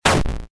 bigshot.ogg